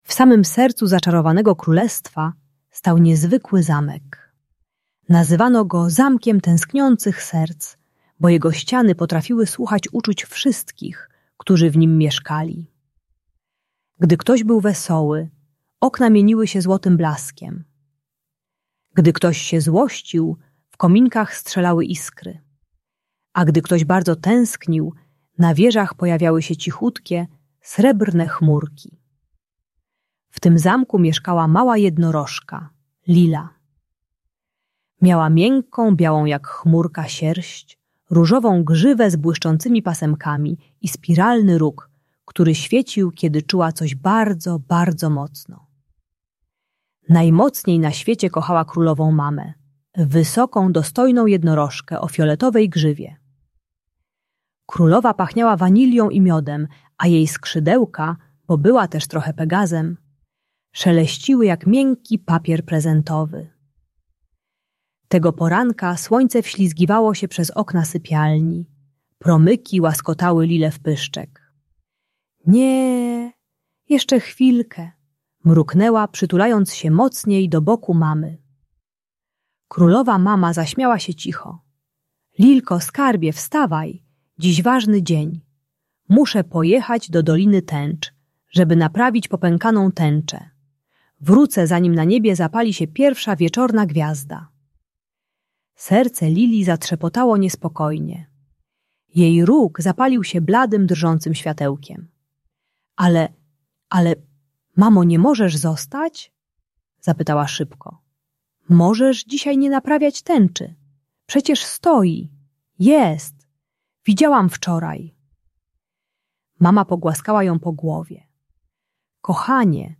Zamek Tęskniących Serc - Przywiązanie do matki | Audiobajka